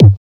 EQ KICK 4 1.wav